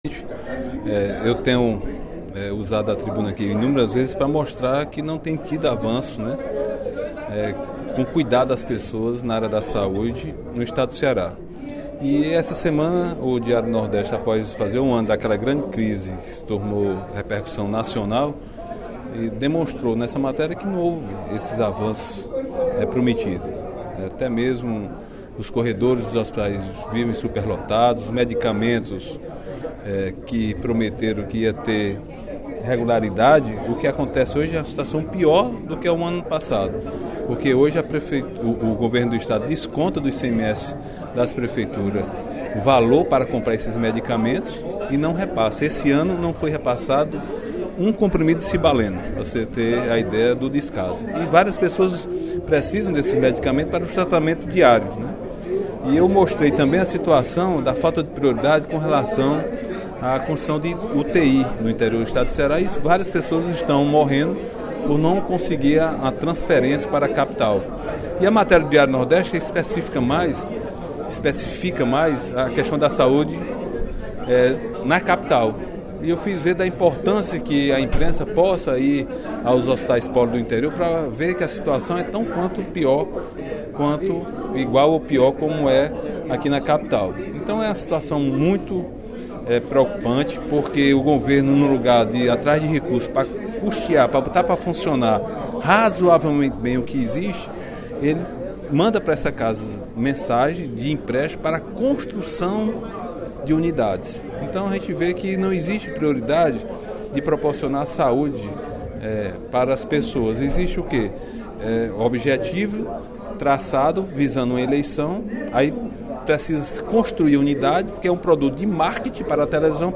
O deputado Agenor Neto (PMDB) comentou, no primeiro expediente da sessão plenária desta terça-feira (03/05), a matéria do jornal Diário do Nordeste sobre a investigação do Tribunal de Contas do Estado (TCE) acerca da gestão da saúde do Estado.